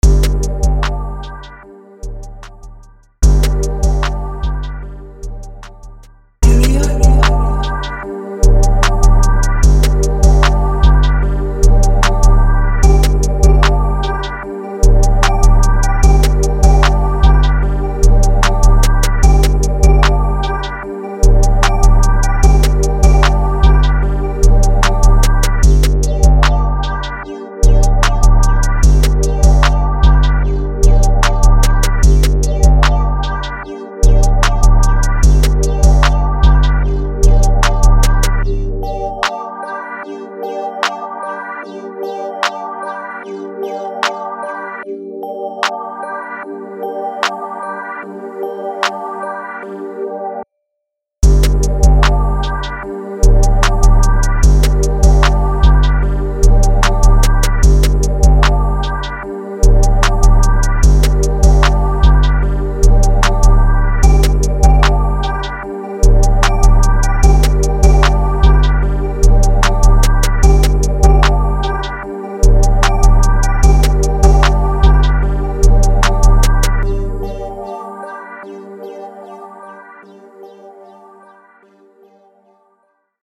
Жанр: Trap